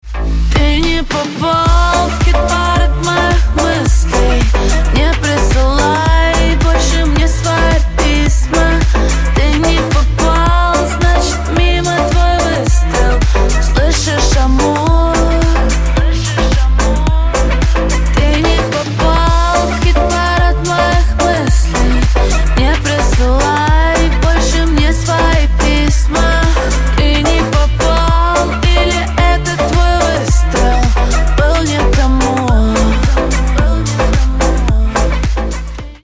• Качество: 128, Stereo
поп
dance